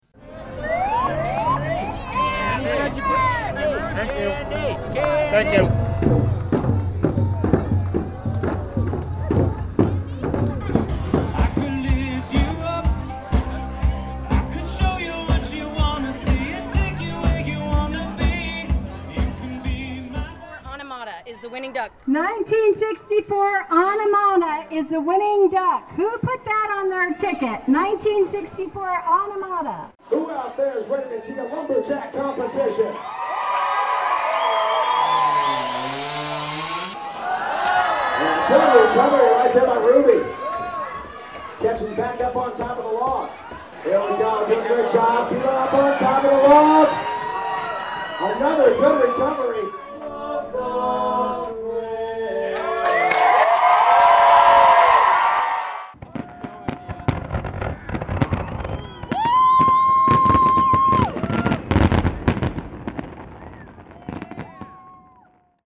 Click here for an audio postcard of the Ketchikan 4th of July celebration.